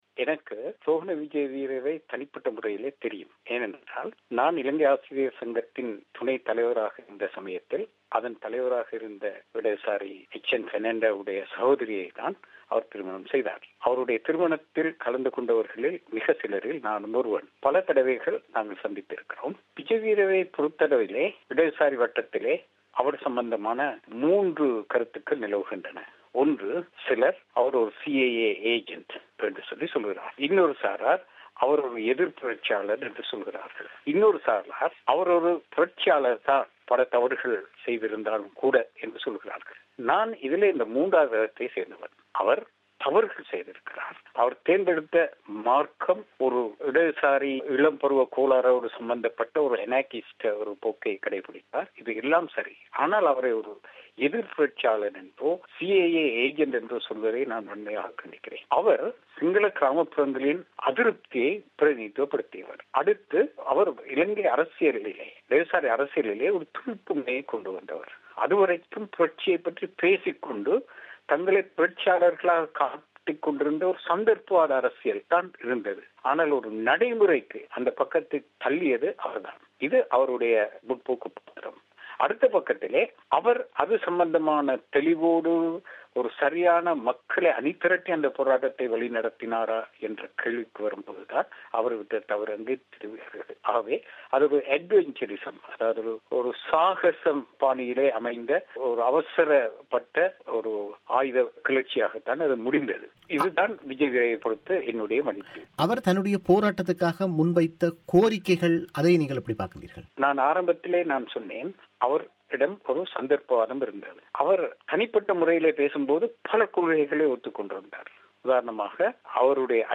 அவரது முழுமையான செவ்வியை இங்கு கேட்கலாம்.